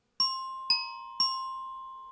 Hovering_hummingbird.ogg